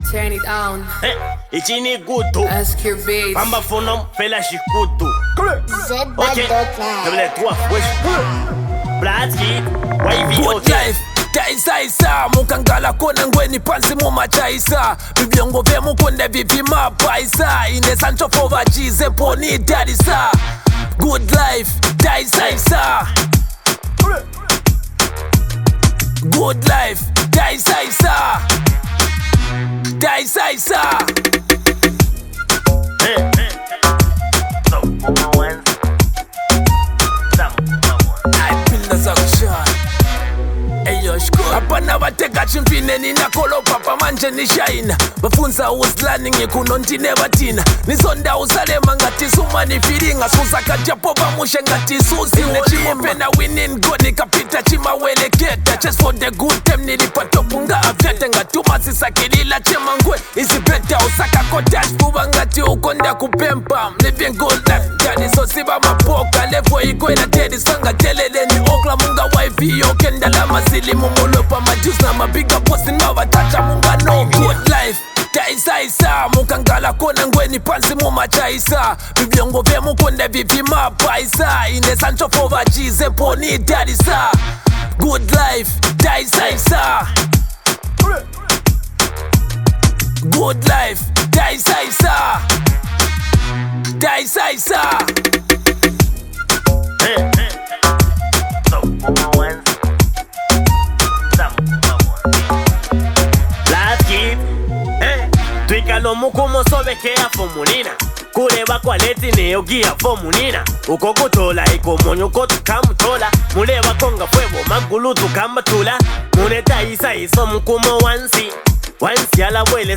From its powerful verses to its dynamic beat